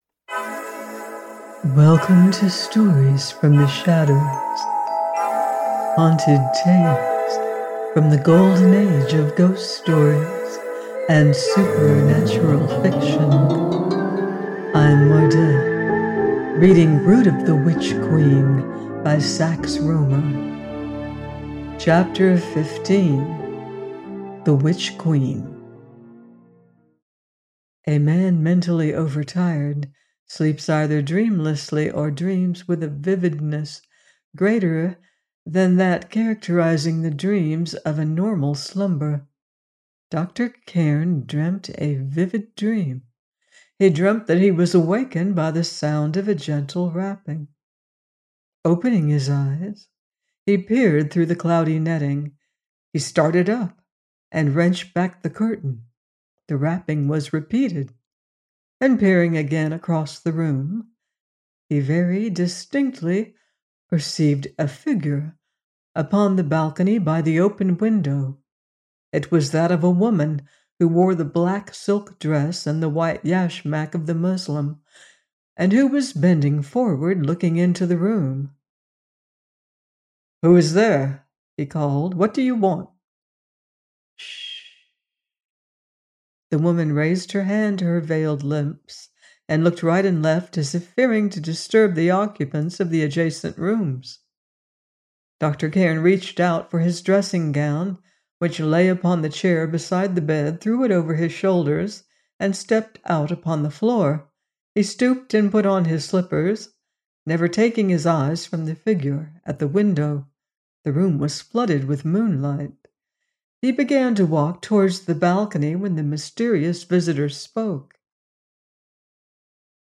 Brood of the Witch Queen – 15 : by Sax Rohmer - AUDIOBOOK